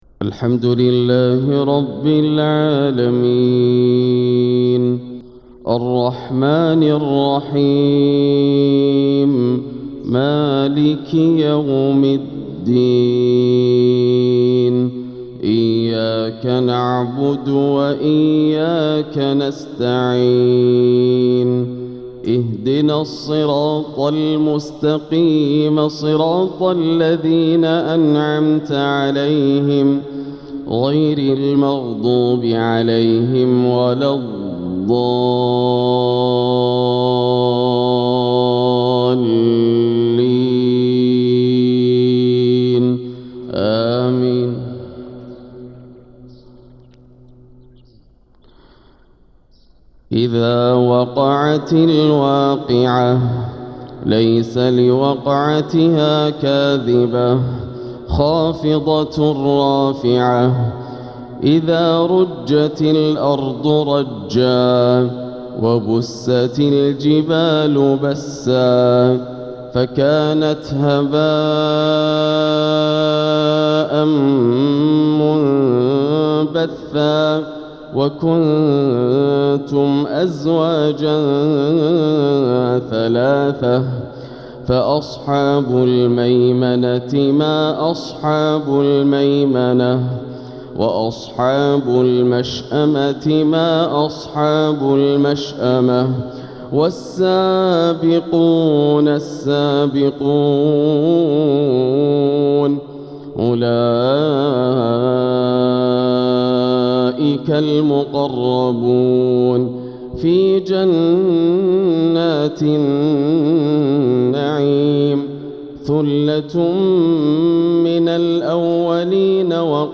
فجر الأربعاء 6-8-1446هـ | من سورة الواقعة 1-74 | Fajr prayer from Surah al-Waqi`ah 5-2-2025 > 1446 🕋 > الفروض - تلاوات الحرمين